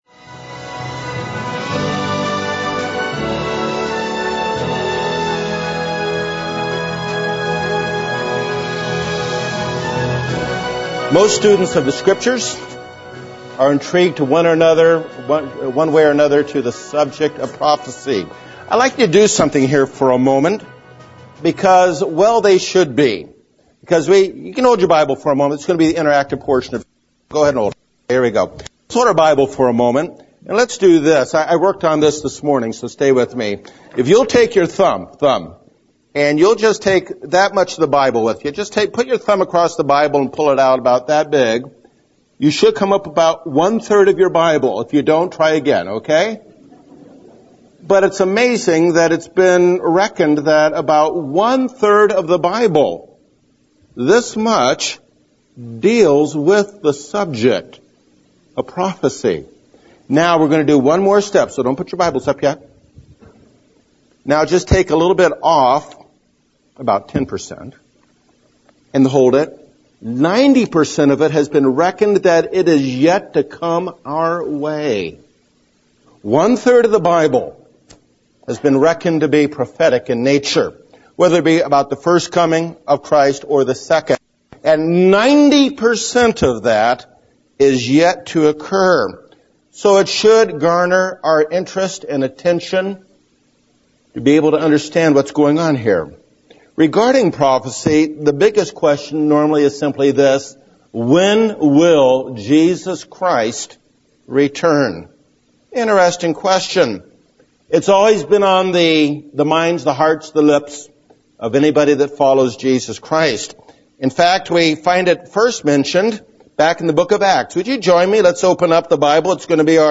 World News and Prophecy Seminar